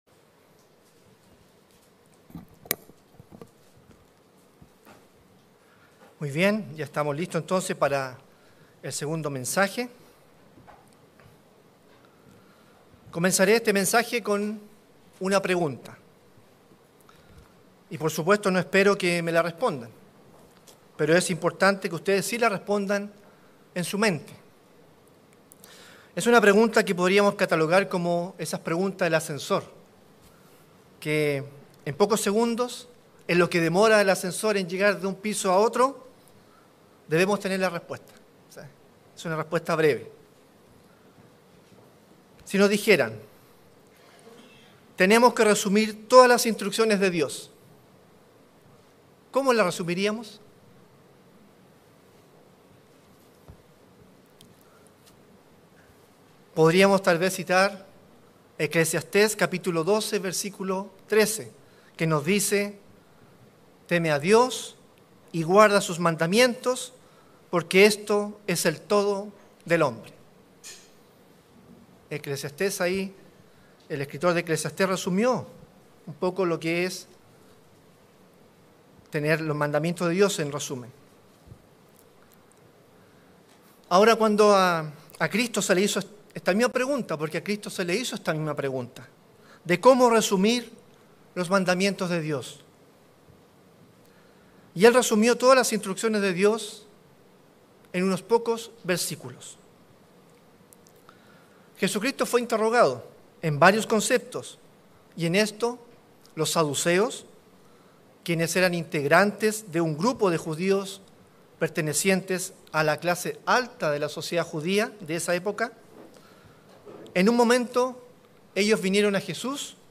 Sermones